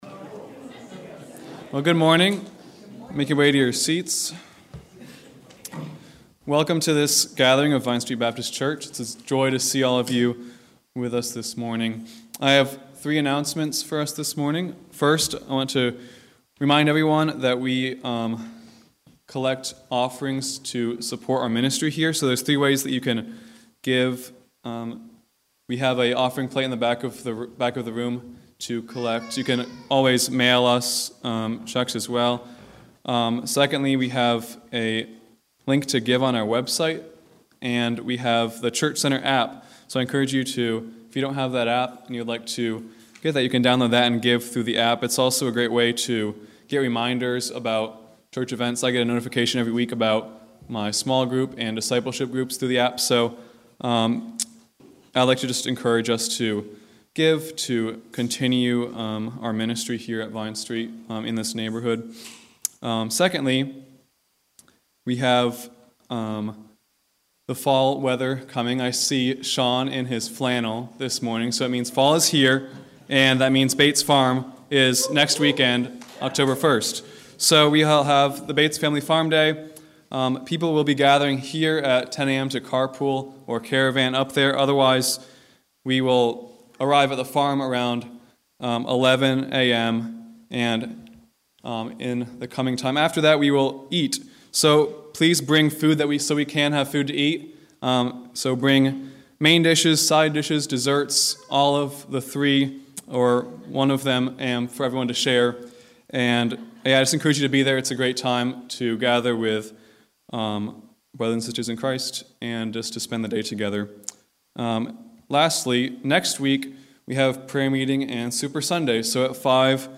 September 25 Worship Audio – Full Service